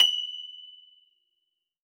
53q-pno24-F5.aif